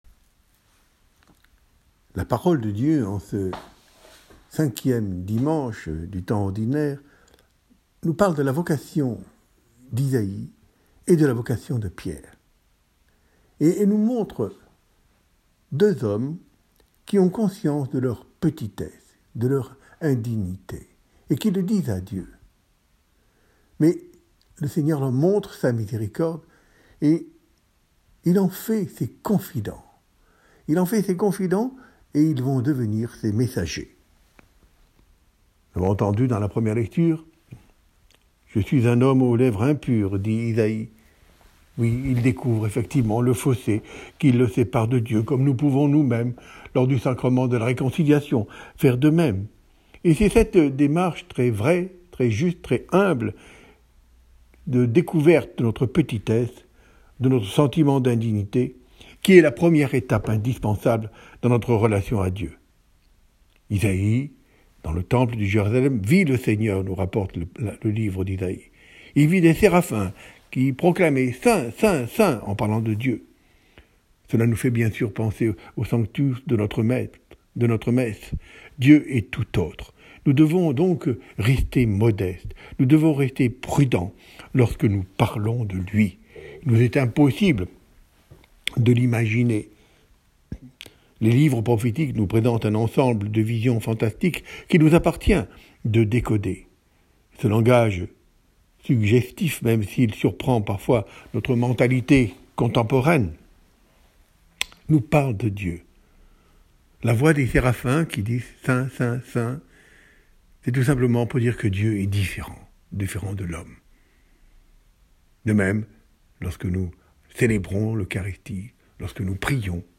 Homélie de Mgr Colomb.
Homélie-6fevrier-2022.m4a